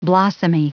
Prononciation du mot blossomy en anglais (fichier audio)
Prononciation du mot : blossomy